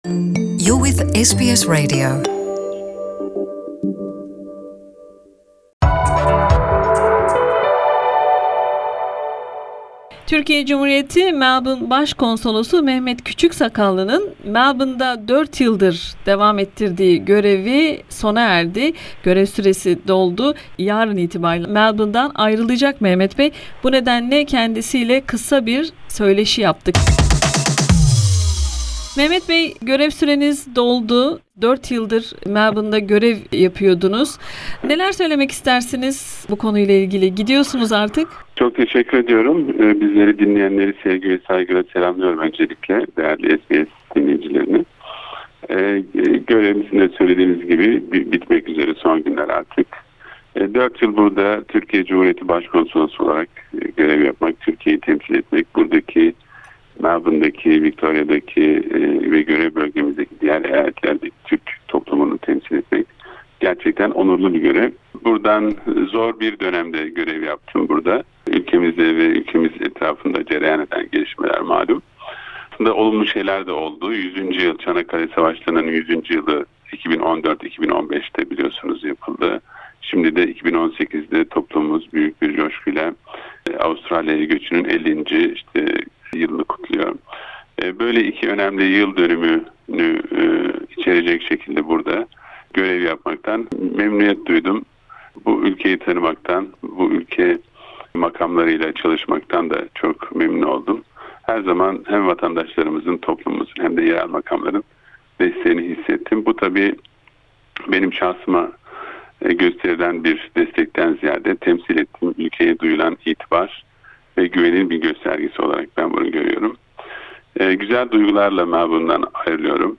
4 yıldır TC Melbourne başkonsolosu olarak görev yapan Mehmet Kuçüksakallı Avustralya'daki son iş gününde yaptığımız söyleşide Avustralya Türk toplumundan helalik istedi.